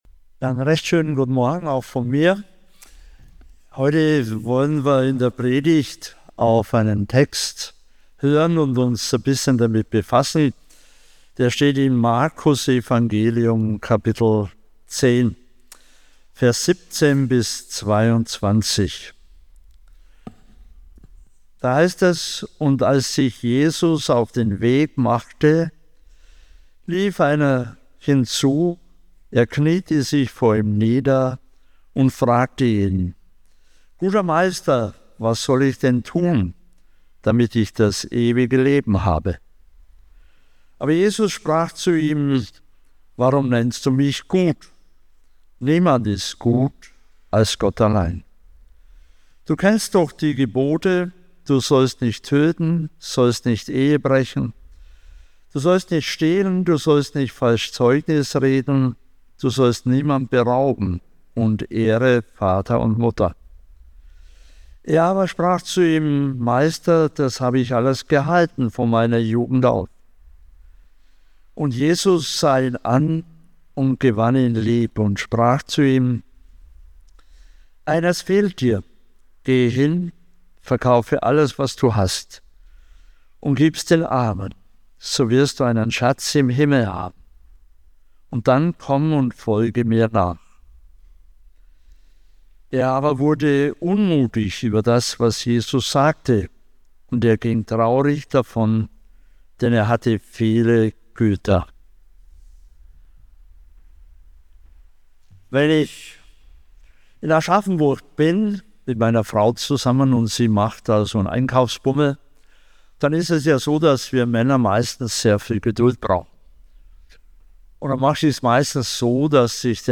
Predigt Podcast